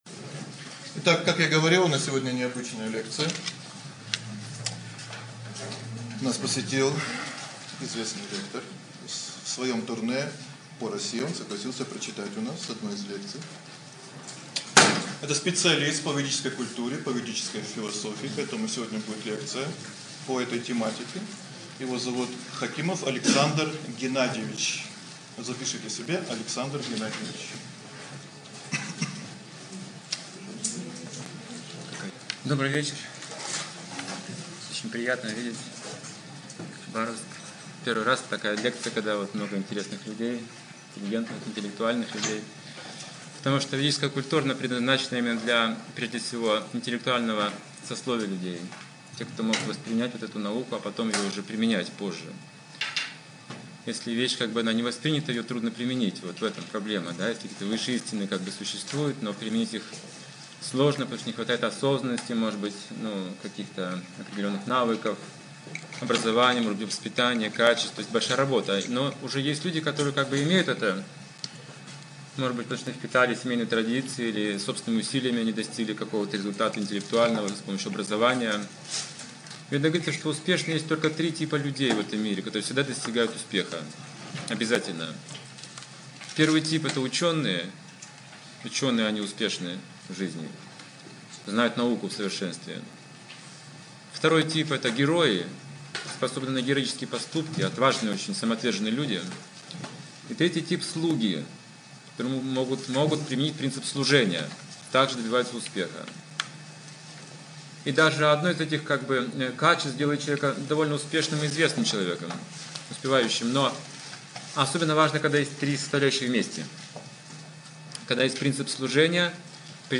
Темы, затронутые в лекции: 3 типа успешных людей Средства достижения цели Знание об устройстве цели Как стать успешным Путь избавления от страданий Путь преодоления кармы Как работает карма Хитросплетения кармы Причины бедности Сознание слуги Бога